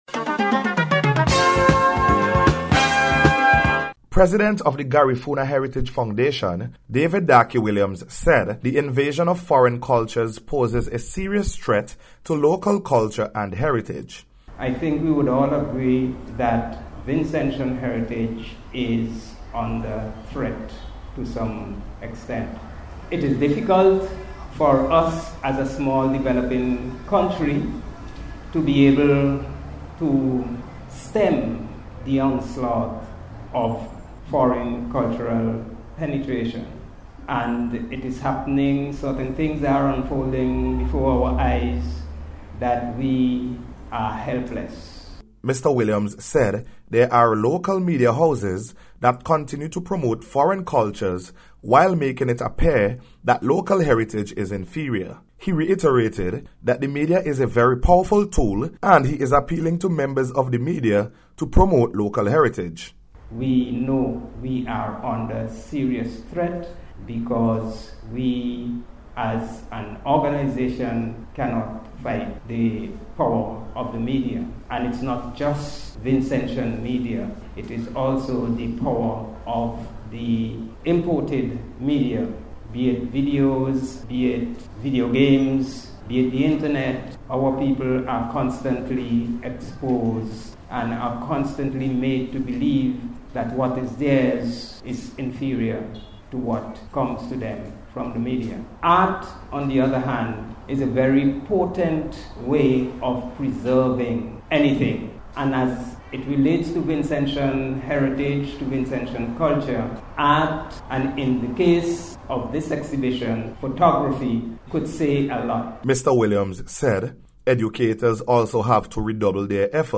LOCAL-HERITAGE-THREAT-REPORT.mp3